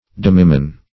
demiman - definition of demiman - synonyms, pronunciation, spelling from Free Dictionary Search Result for " demiman" : The Collaborative International Dictionary of English v.0.48: Demiman \Dem"i*man`\, n. A half man.